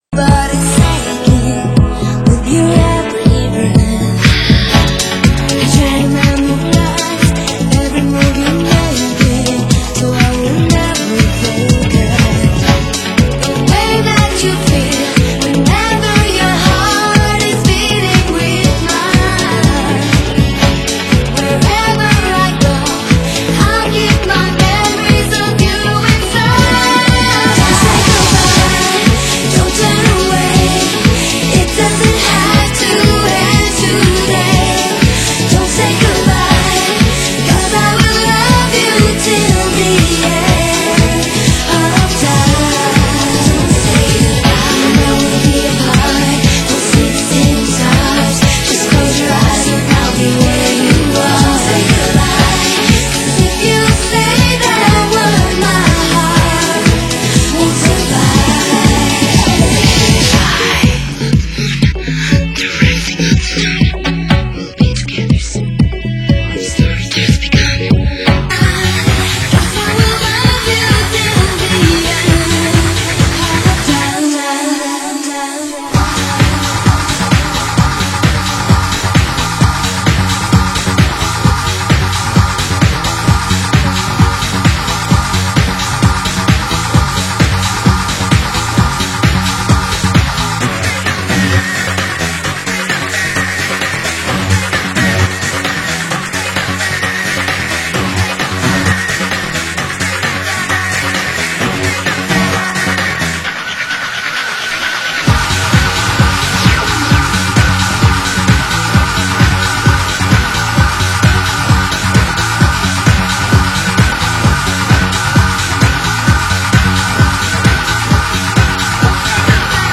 Genre: House
Genre: Euro House